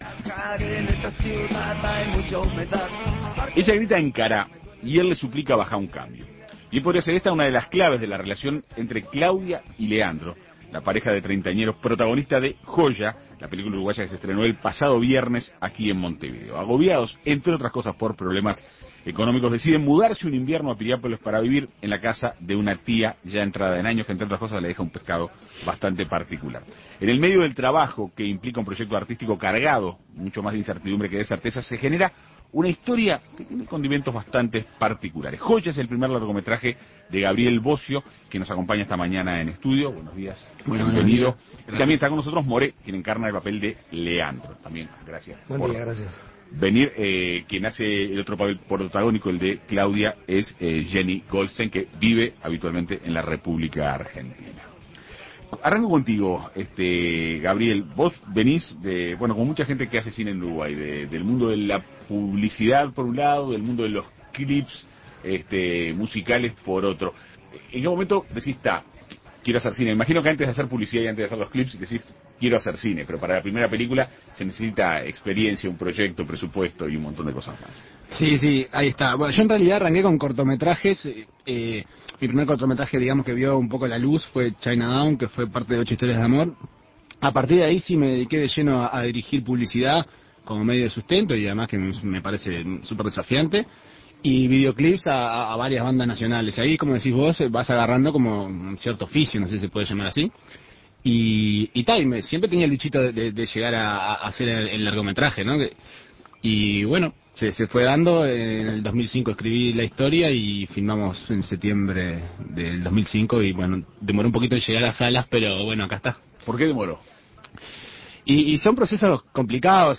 Este viernes dialogaron con En Perspectiva Segunda Mañana